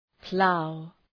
Προφορά
{plaʋ}